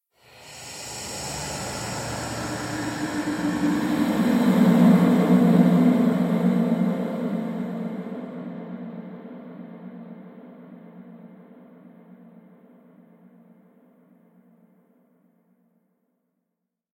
Звук Призрака Женского Ужаса растянутый
zvuk_prizraka_zhenskogo_uzhasa_rastyanutij_mjv.mp3